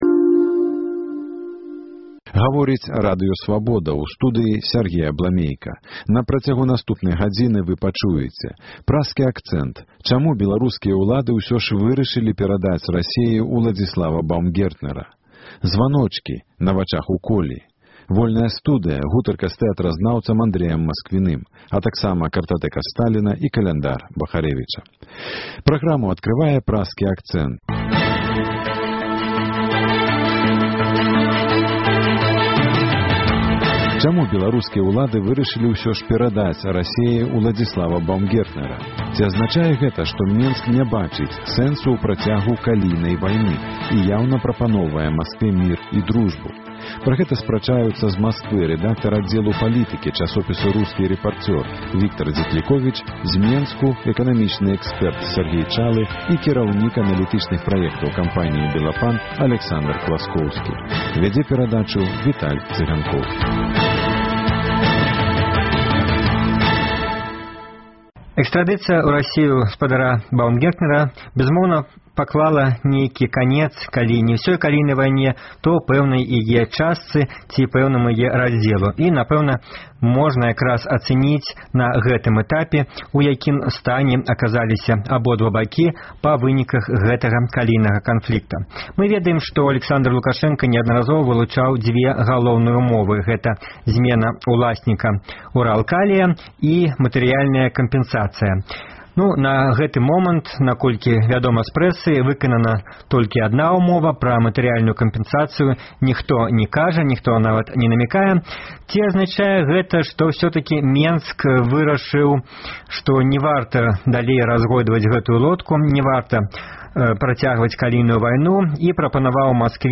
Ці прывядзе зьмена ўласьнікаў “Уралкалію” да пажаданых для кіраўніцтва Беларусі вынікаў? На гэтыя ды іншыя пытаньні ў перадачы Праскі Акцэнт адказваюць: